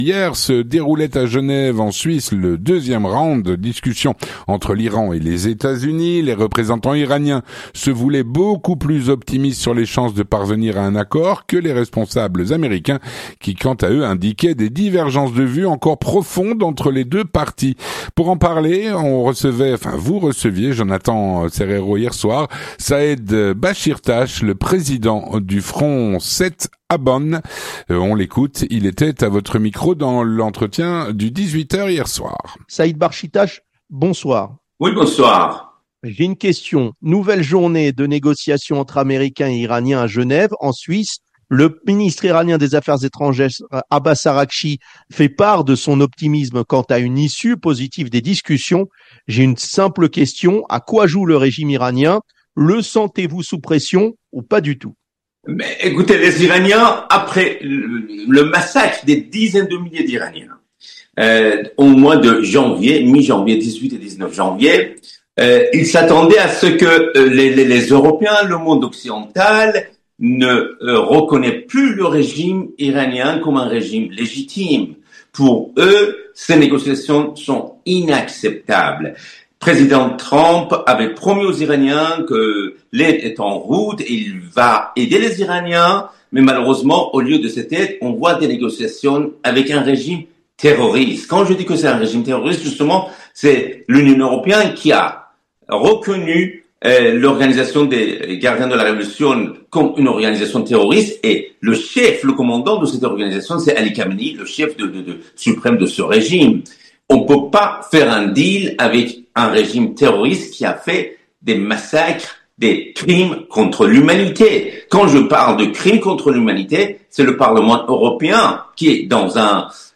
L'entretien du 18H - Le deuxième round de discussion entre l’Iran et les Etats-Unis.